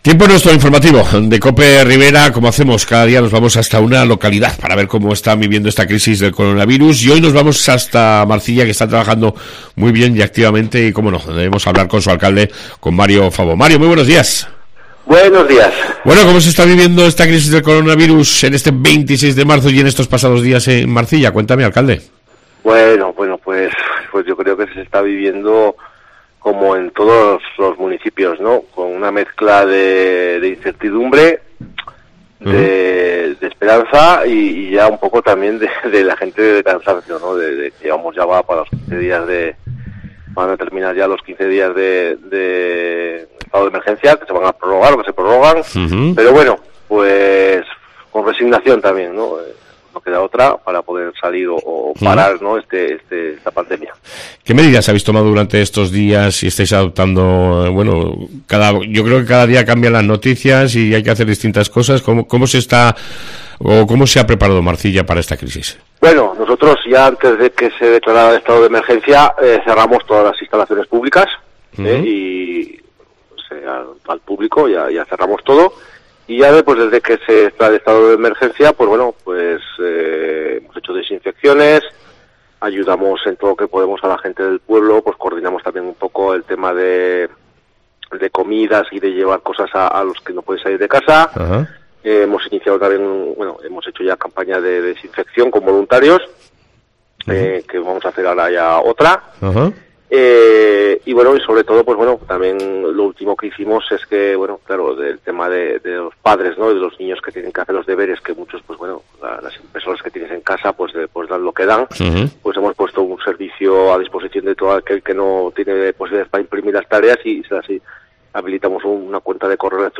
Entrevista con el alcalde de Marcilla Mario Fabo